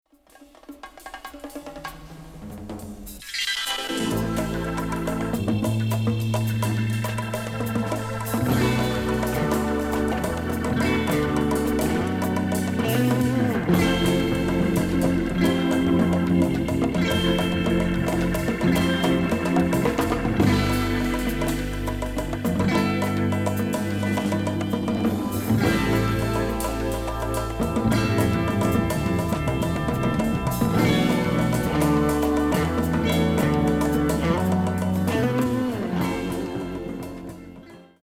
虫の音から始まるスピリチュアル作。